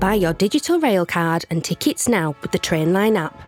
Northern
Warm, Trusting, Friendly